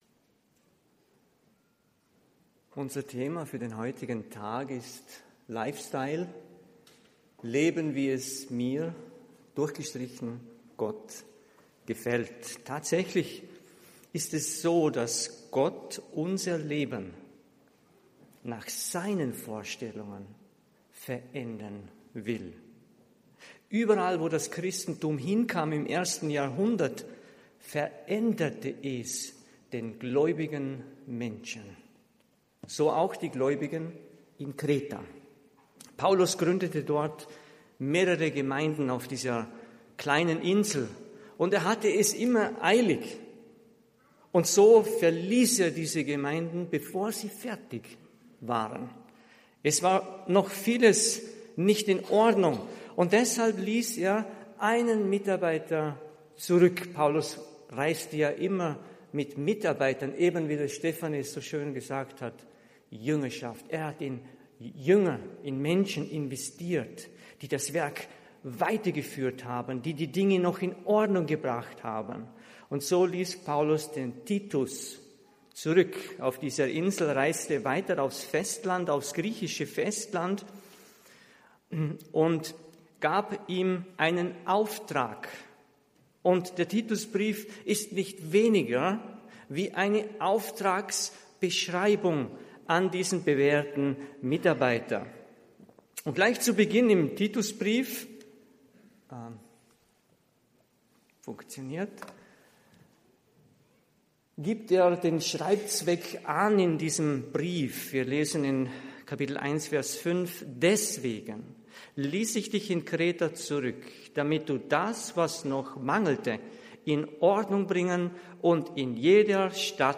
Gemeindetag_2022_Predigt.mp3